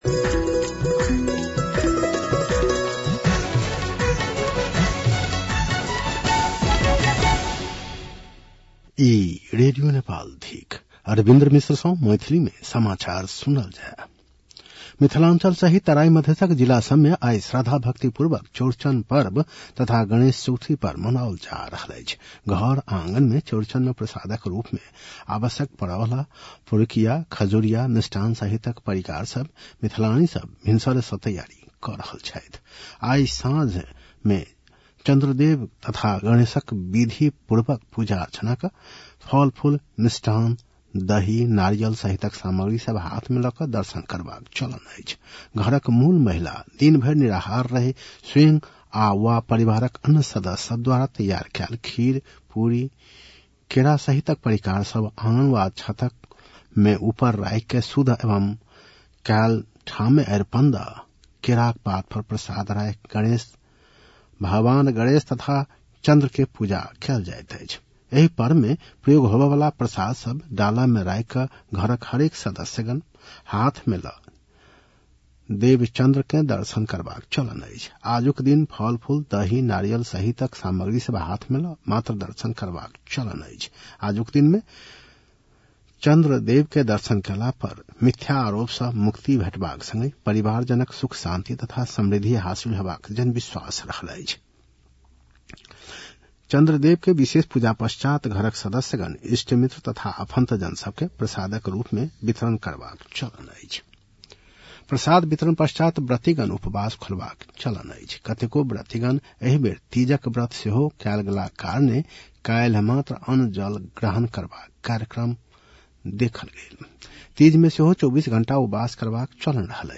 मैथिली भाषामा समाचार : १० भदौ , २०८२